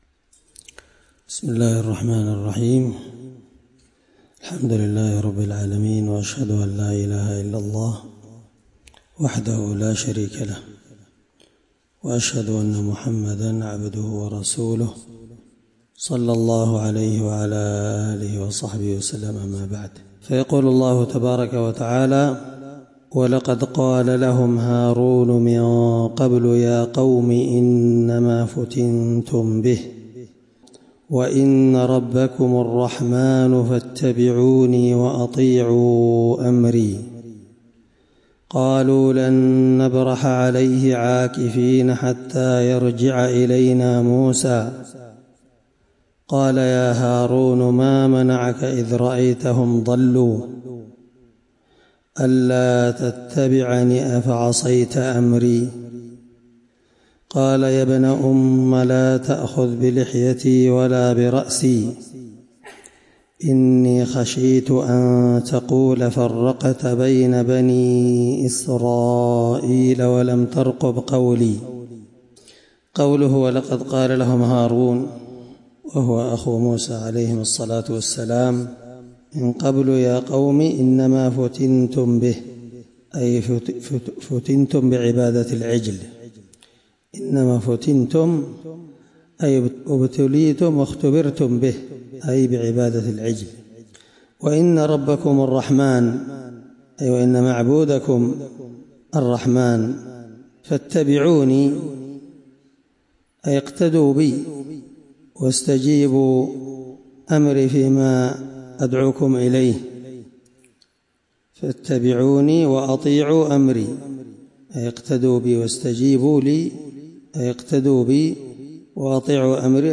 مع قراءة لتفسير السعدي